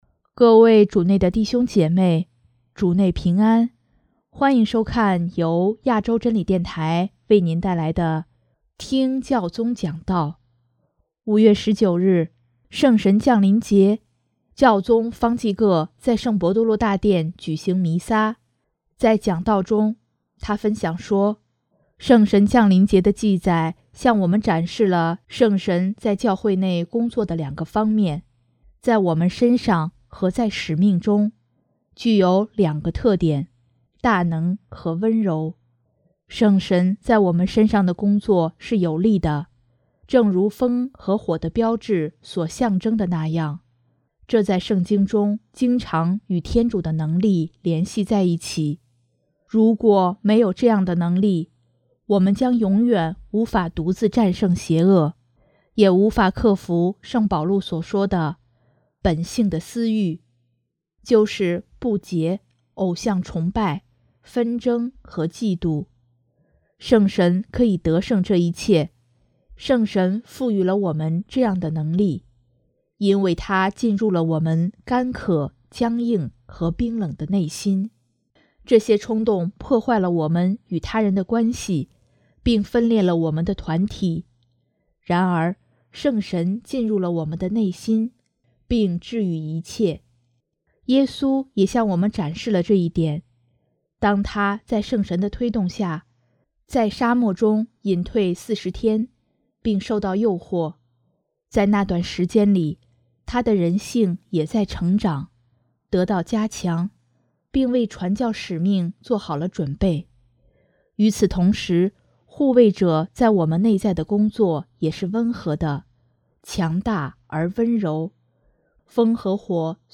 【听教宗讲道】|有力而温柔的圣神，改变我们的心
5月19日，圣神降临节，教宗方济各在圣伯多禄大殿举行弥撒，在讲道中，他分享说：